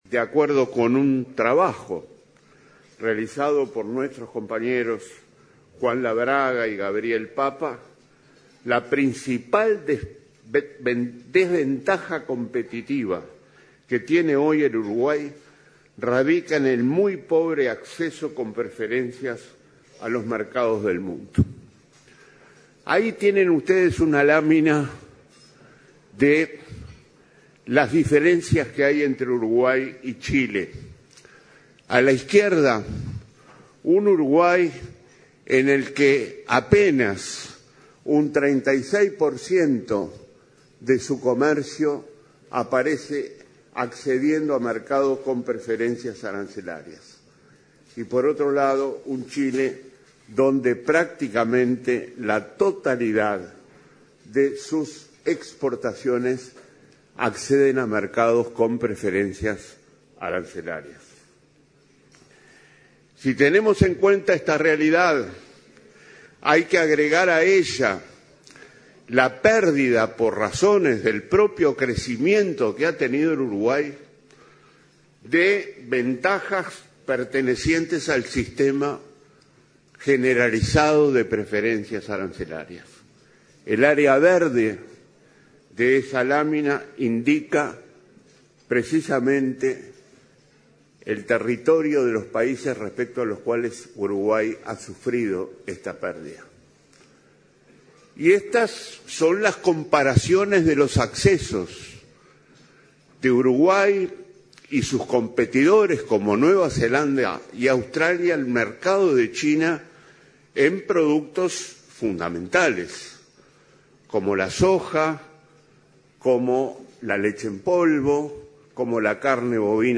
La principal desventaja competitiva de Uruguay está en el pobre acceso con preferencias a los mercados del mundo, dijo el ministro Danilo Astori en ADM. Poco más del 30 % de las exportaciones de Uruguay accede a mercados con preferencias arancelarias, mientras que Chile alcanza el 96 %.